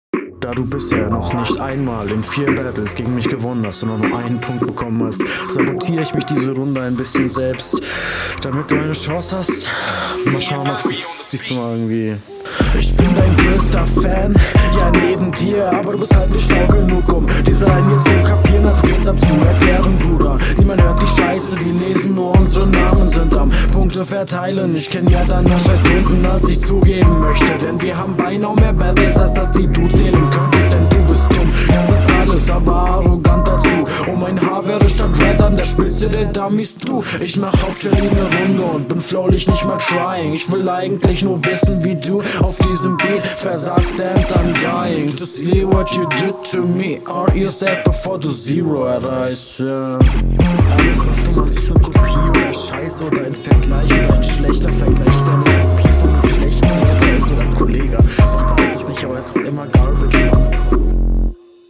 stimme zu leise gemastert finde ich, aber punches hatten safe inhalt und flow, technik nicht …